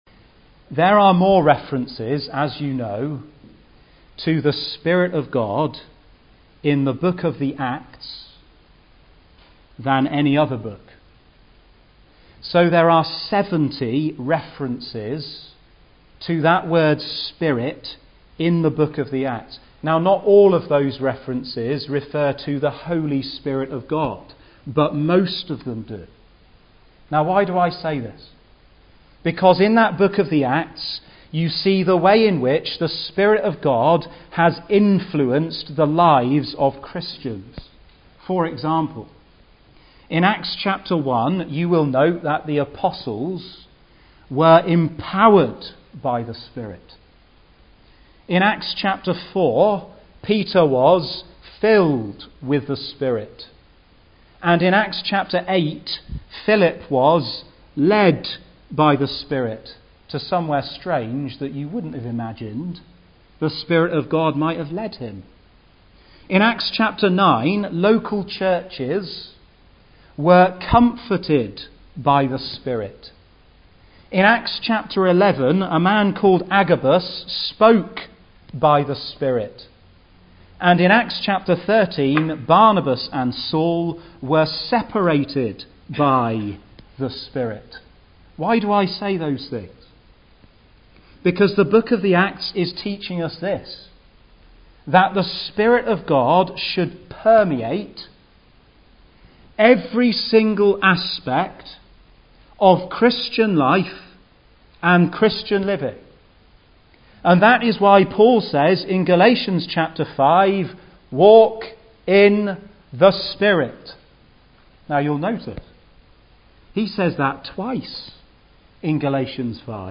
He takes up his subject under the following headings: Insurance (seal/earnest/anointing), Indwelling (life/teaching/holiness), Imprint (fruit) and Inhibiting (grieving/quenching). A wide-ranging message on the multifaceted ministry of the Holy Spirit today (Message preached 1st Oct 2015)